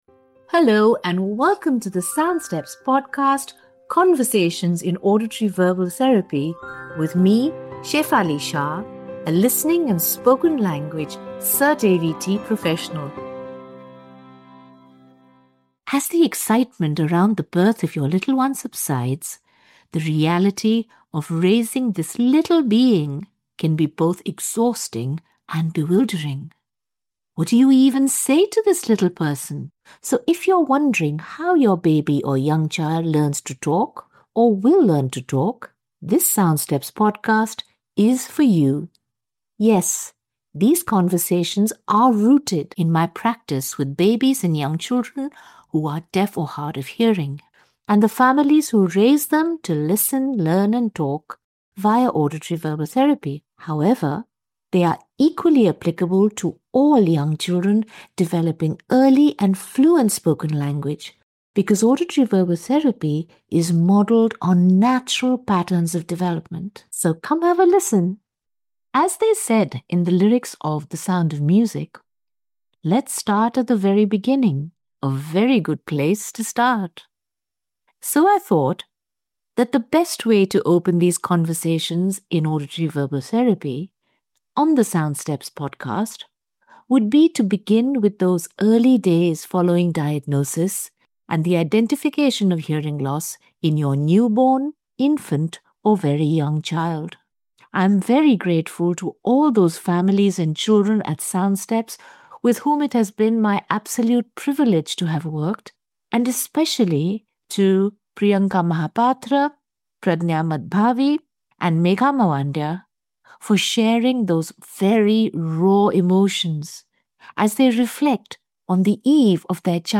In openhearted, in-person conversations